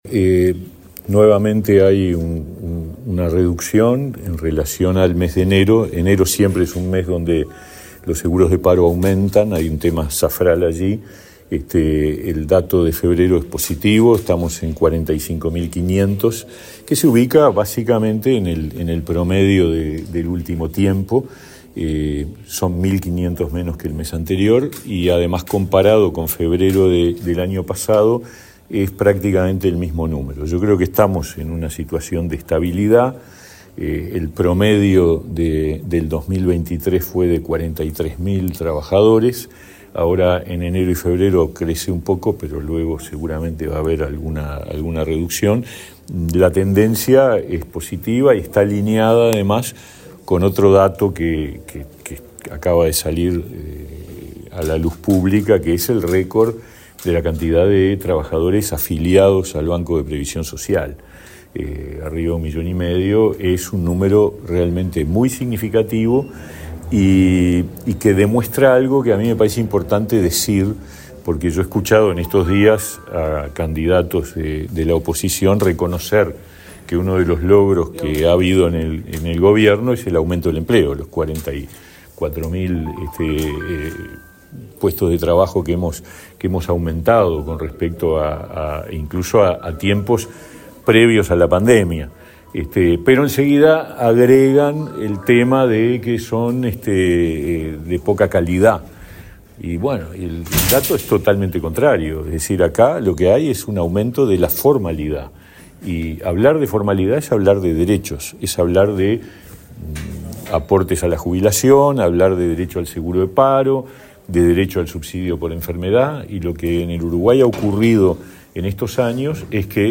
Declaraciones del ministro de Trabajo, Pablo Mieres